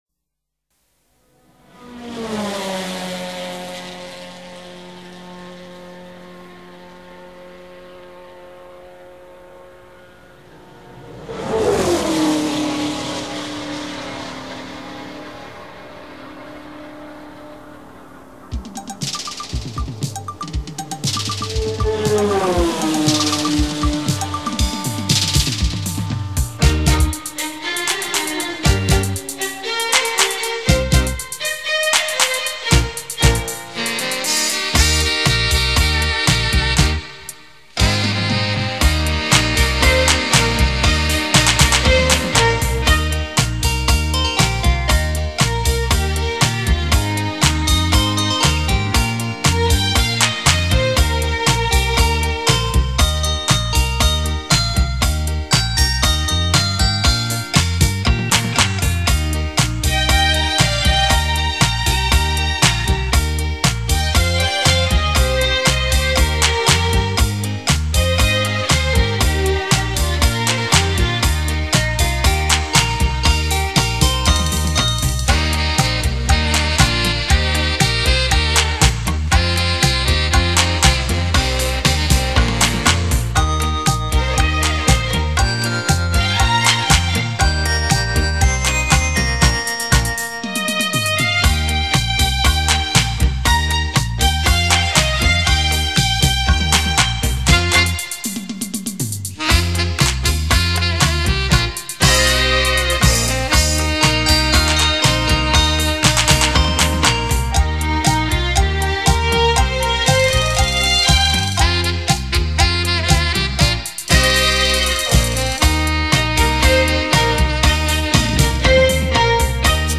音乐类型： 轻音乐　　　　　    　  　.
码　　率： Loseless Joint-Stereo　    .